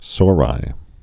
(sôrī)